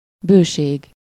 Ääntäminen
France: IPA: [a.bɔ̃.dɑ̃s]